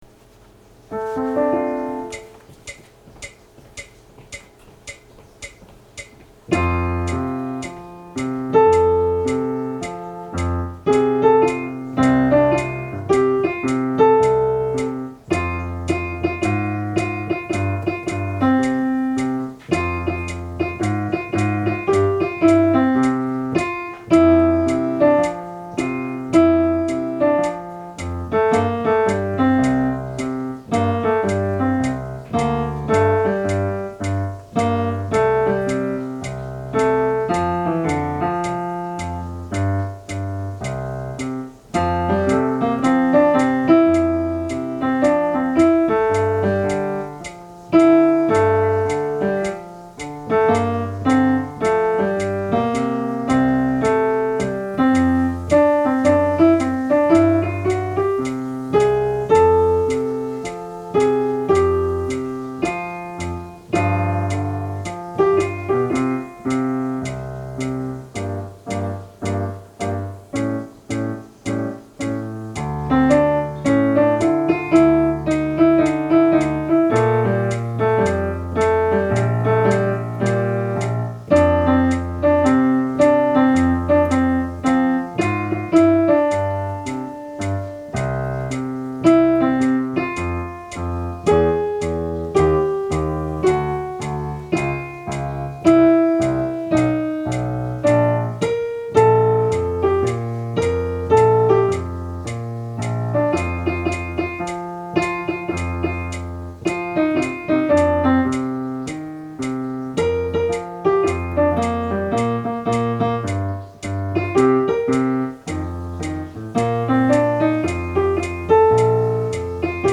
コーラスのオーディオファイル
４声
テンポを練習用に落としています。